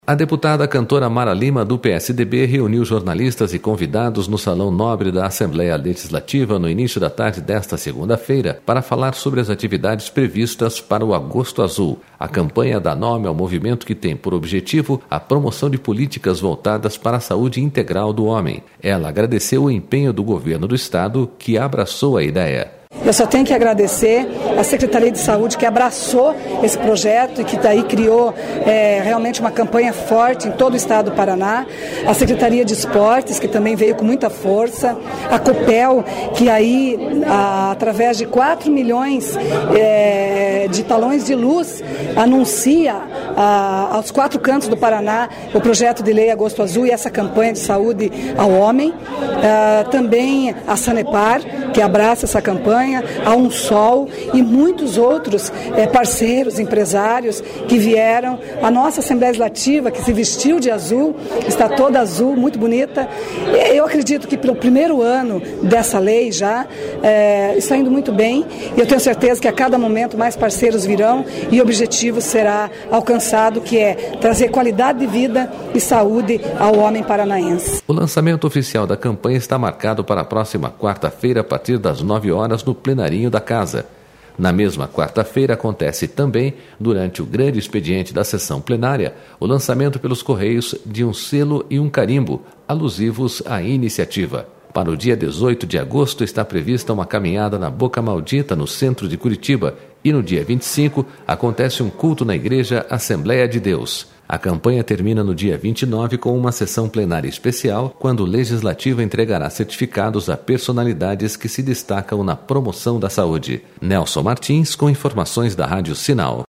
Deputada Mara Lima fala a jornalistas sobre atividades do
A deputada Cantora Mara Lima, do PSDB, reuniu jornalistas e convidados no Salão Nobre da Assembleia Legislativa no início da tarde desta segunda-feira, para falar sobre as atividades previstas para o “Agosto Azul”.//A campanha dá nome ao movimento que tem por objetivo a promoção de políticas voltada...